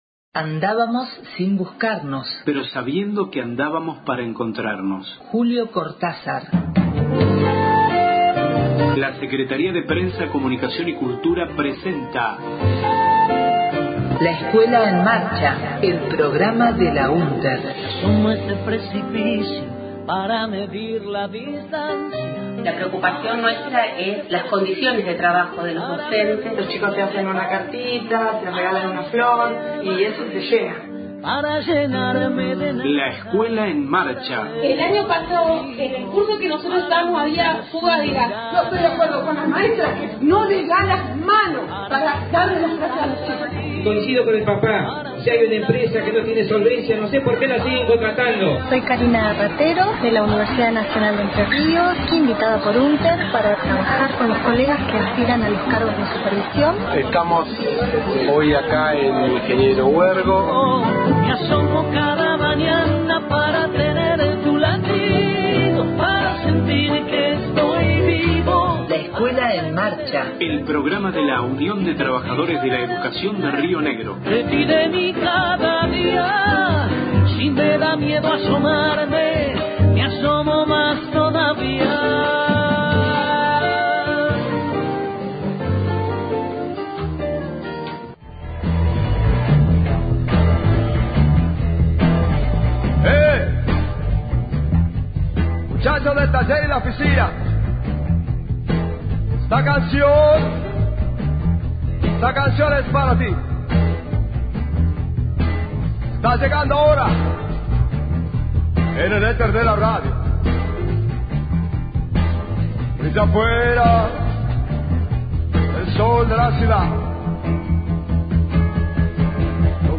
LEEM radio, 10/06/16. Vicente Zito Lema y un especial con la voz de Rodolfo Walsh recordando los fusilamientos de José León Suarez a 60 años de este crimen.